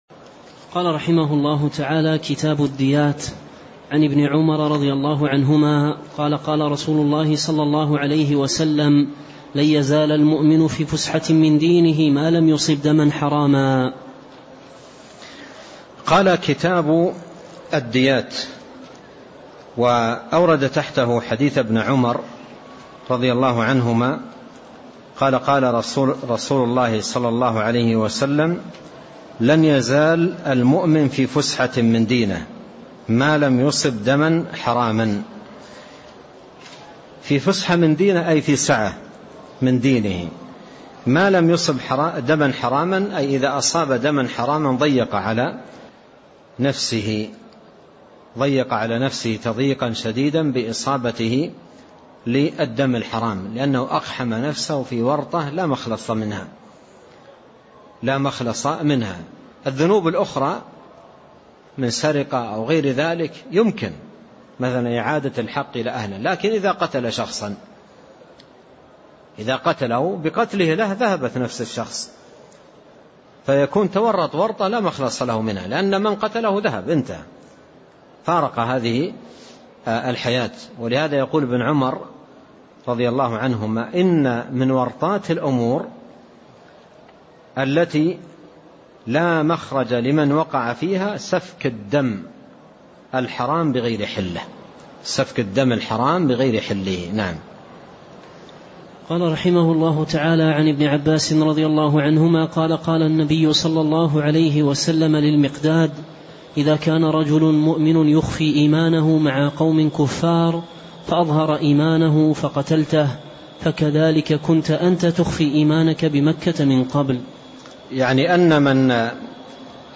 تاريخ النشر ٢٢ صفر ١٤٣٦ هـ المكان: المسجد النبوي الشيخ